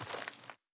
added stepping sounds
Mud_Mono_01.wav